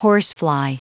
Prononciation du mot horsefly en anglais (fichier audio)
Prononciation du mot : horsefly